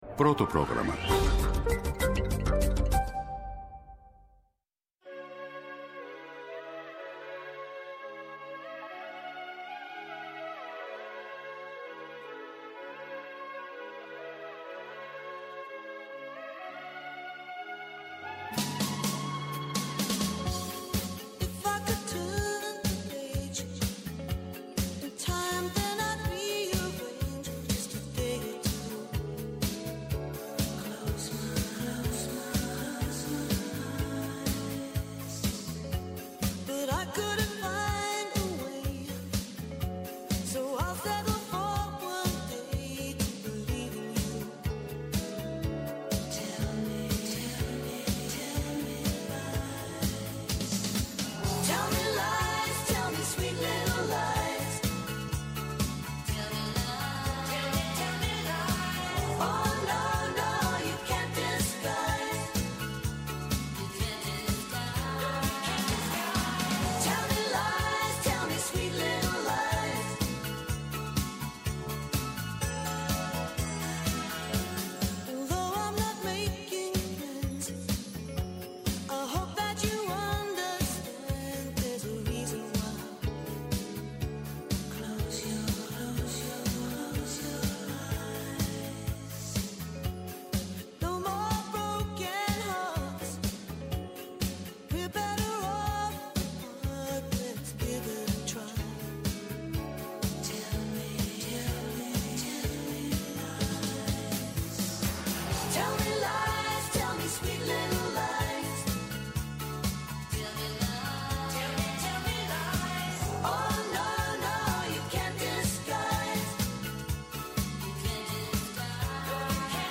σύμβουλος επικοινωνίας
μετεωρολόγος